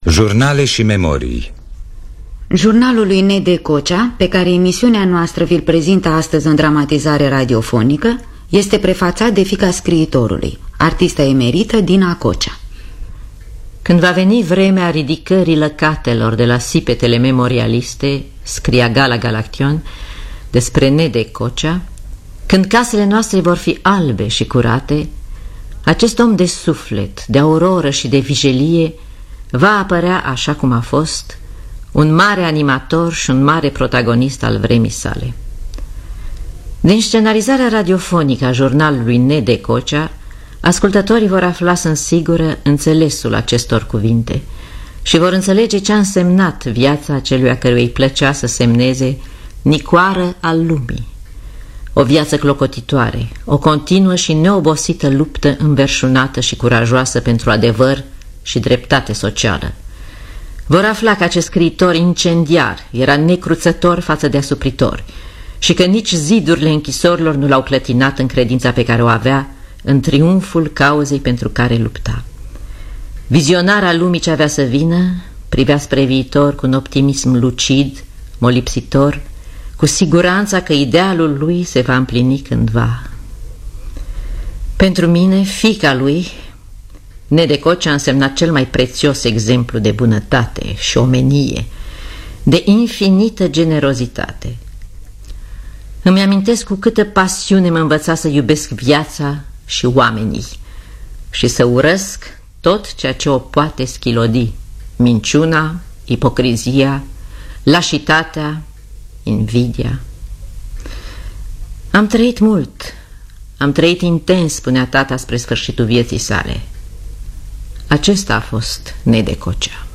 Biografii, Memorii: Jurnalul Lui N. D. Cocea (1974) – Teatru Radiofonic Online